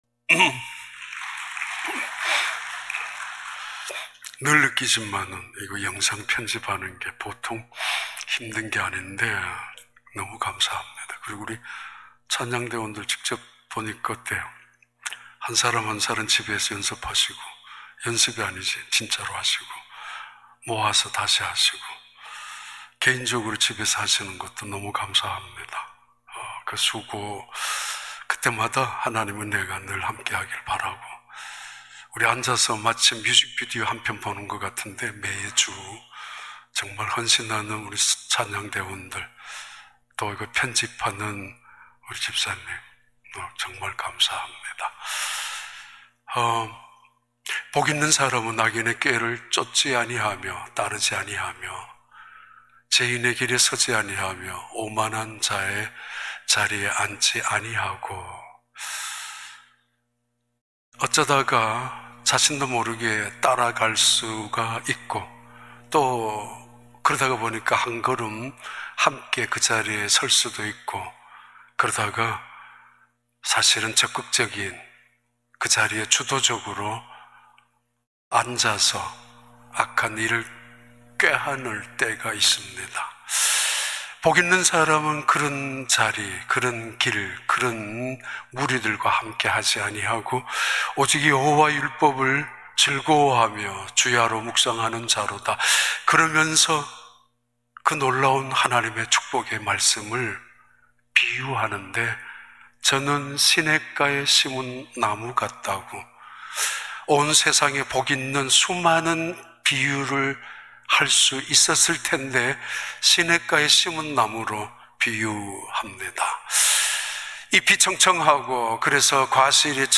2021년 3월 14일 주일 4부 예배